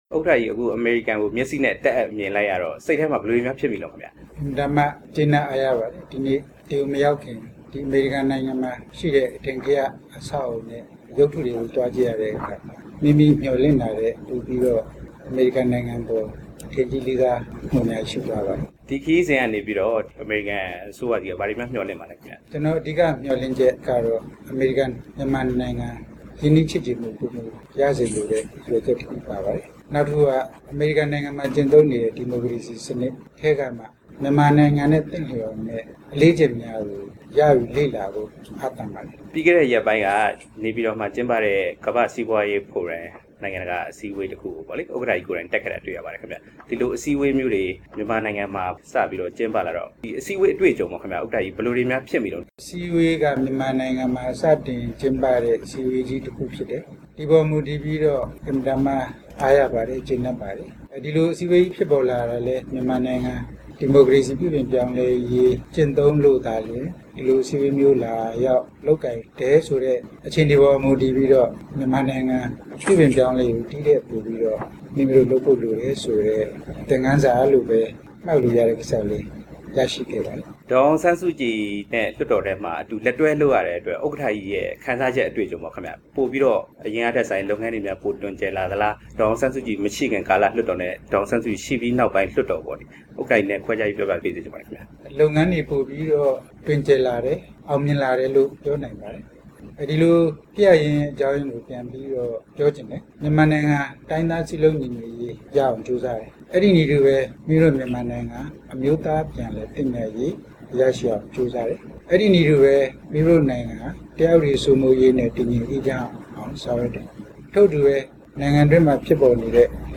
ပြည်သူ့လွှတ်တော်ဥက္ကဌ သူရဦးရွှေမန်းနှင့်　RFA　တွေ့ဆုံမေးမြန်းချက်